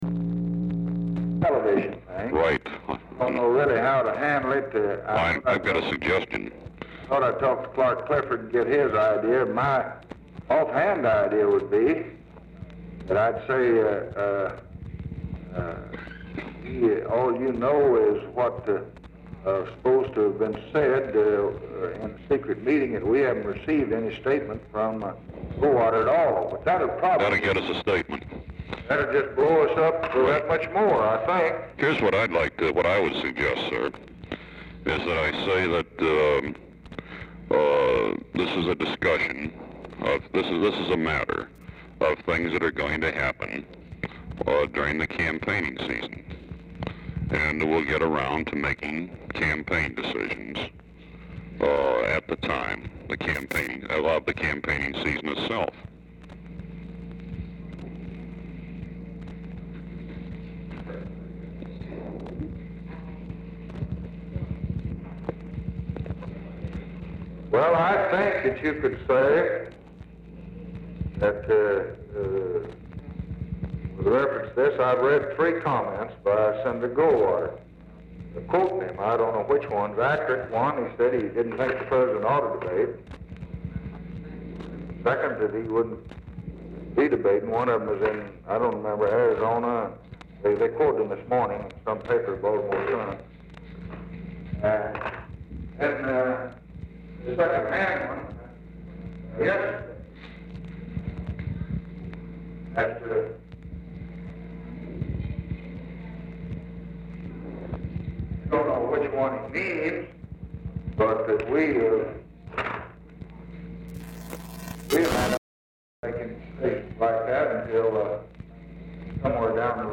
LBJ ON SPEAKERPHONE
Format Dictation belt
Location Of Speaker 1 Oval Office or unknown location
Specific Item Type Telephone conversation